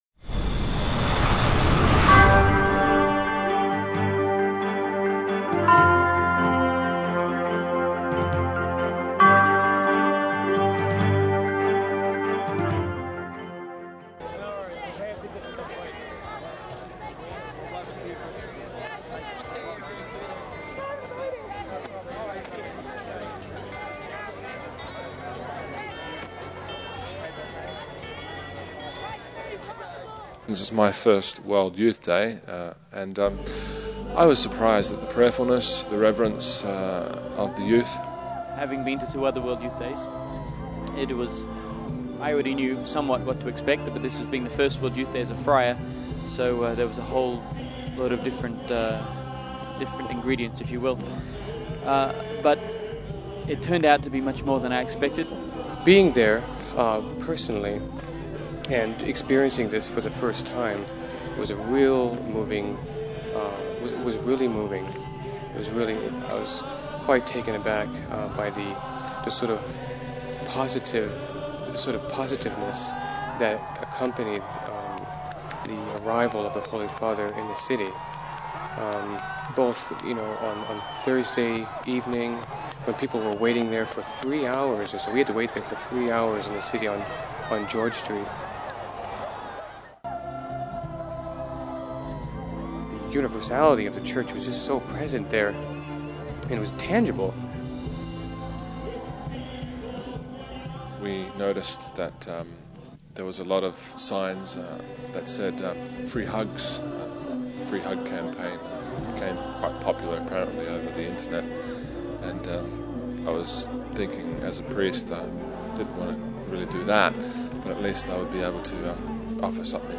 The friars from down under in our Perth, Australian friary just sent this video of their trip to World Youth Day in Sidney (other side of Australia.)